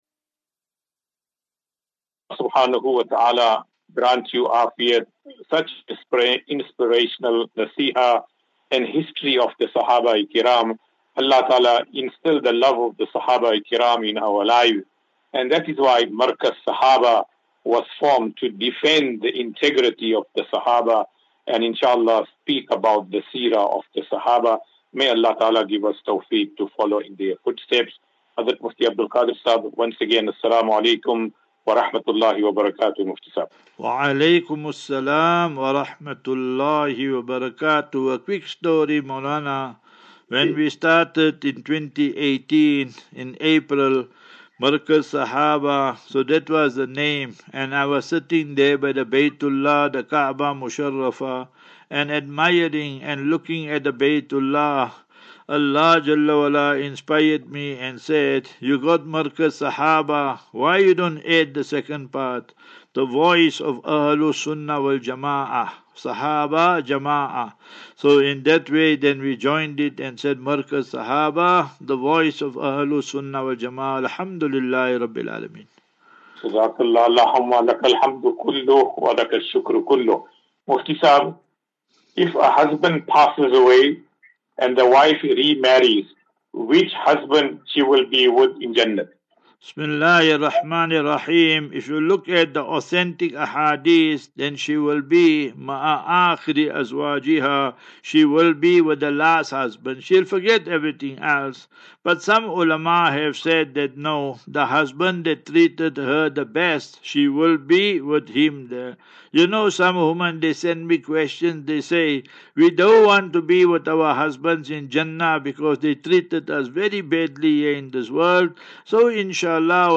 View Promo Continue Install As Safinatu Ilal Jannah Naseeha and Q and A 30 Mar 30 March 2024.